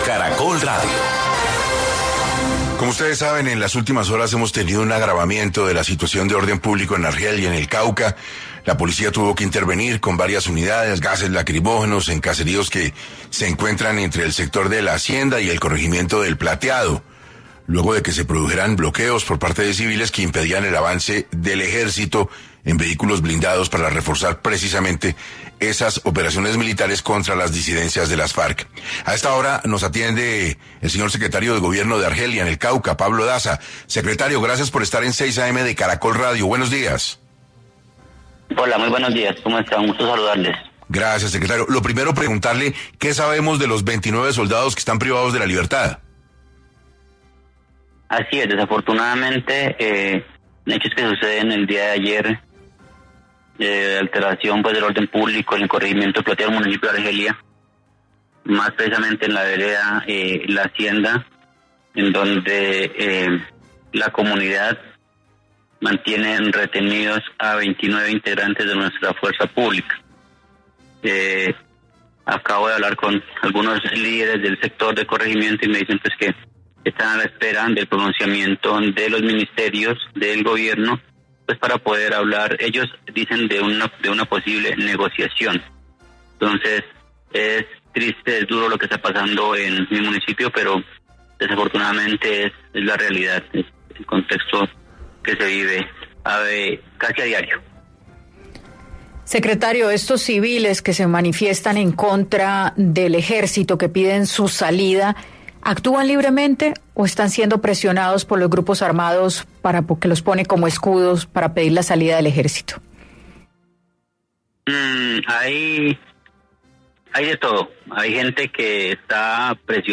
En 6AM de Caracol Radio, Pablo Daza, secretario de gobierno de Argelia, Cauca, detalló la difícil situación de orden público que vive el municipio, asegurando que es mucho más compleja que los hechos ocurridos del secuestro de los uniformados.